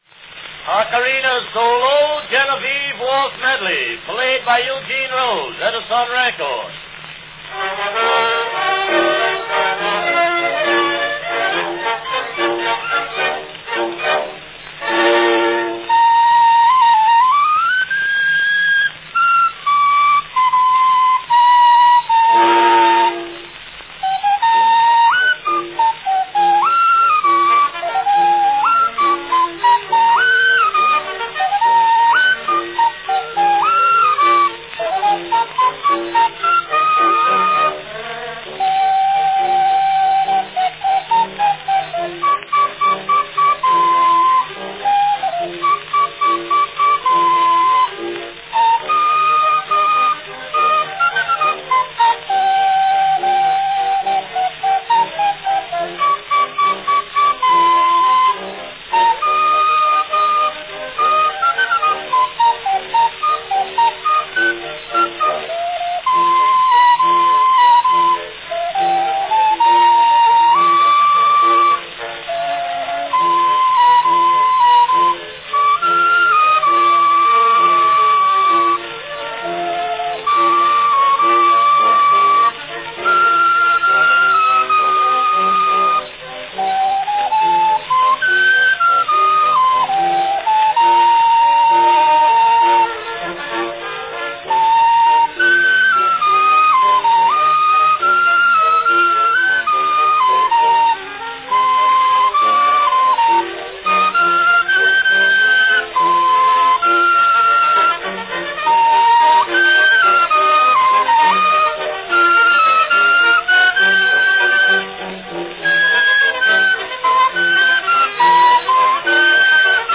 A great novelty recording:
Category Ocarina
Enjoy this unusual record - one of the first solo recordings of the ocarina on wax cylinder.
This specially arranged waltz solo, with orchestra accompaniment, is the result.   The ocarina makes an unusually loud and clear Record, and this selection will undoubtedly be much in demand.